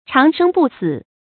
長生不死 注音： ㄔㄤˊ ㄕㄥ ㄅㄨˋ ㄙㄧˇ 讀音讀法： 意思解釋： 生命長存，永不死亡。